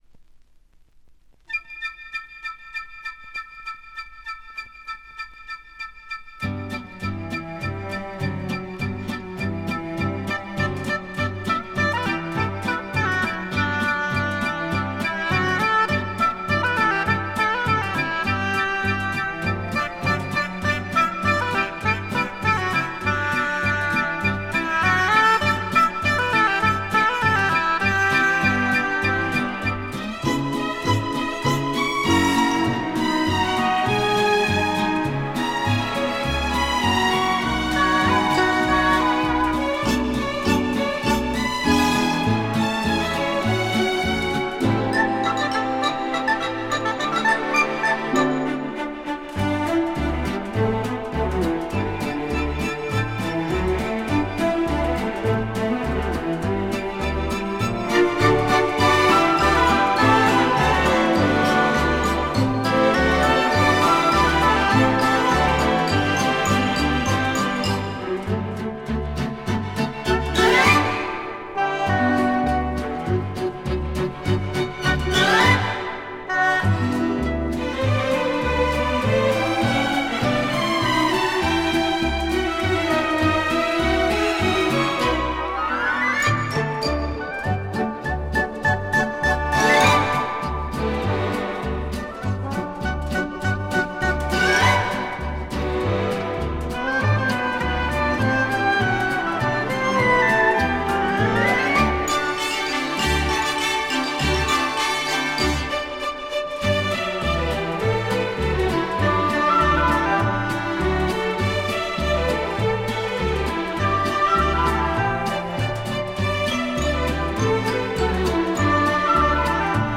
Жанр: Easy Listening, Christmas